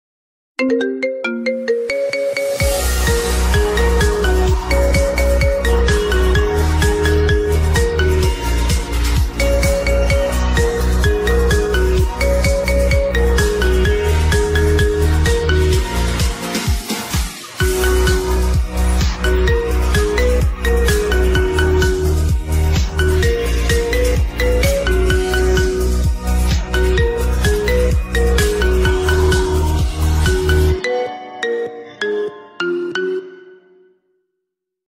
iPhone Ringtones